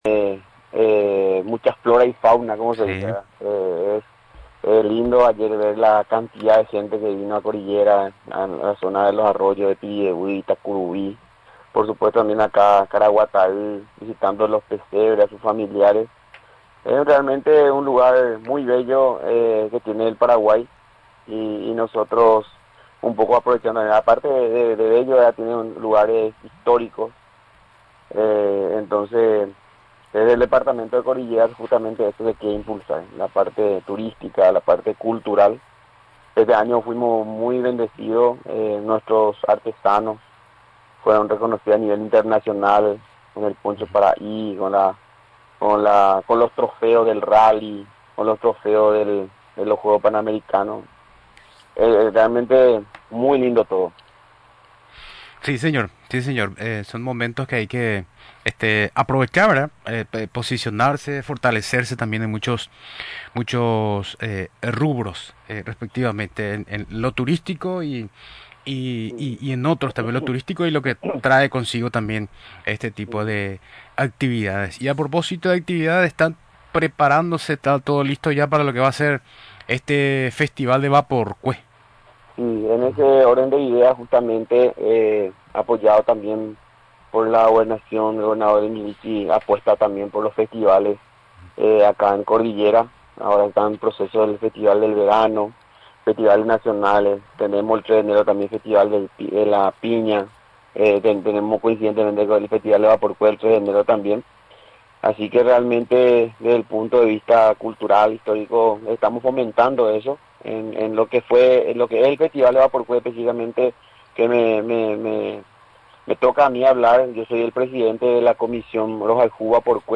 El próximo sábado 3 de enero, se realizará el Festival del Vapor Cué, informó este lunes el jefe de Gabinete de la Gobernación de las Cordilleras, Patricio Ortega.
Durante la entrevista en Radio Nacional del Paraguay, mencionó que el evento comenzará a las 18:00 con un sunset a cargo del Dj paraguayo.